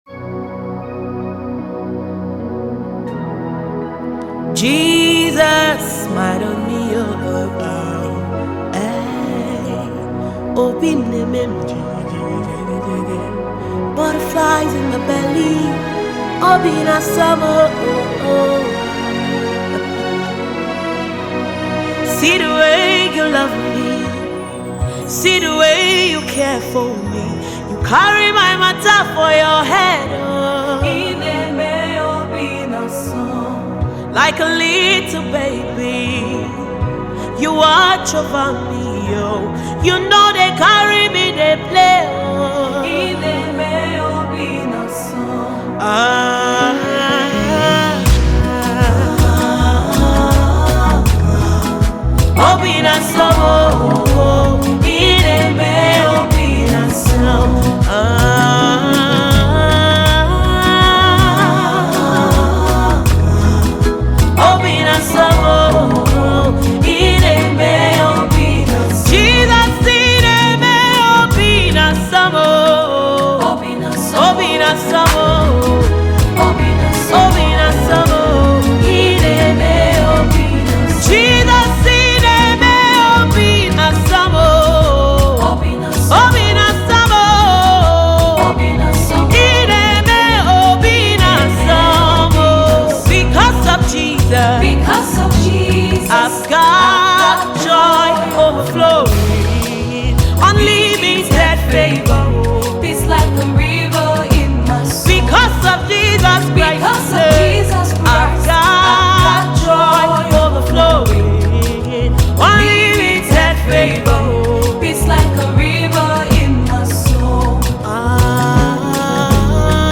Gospel Music
I like your melodious songs,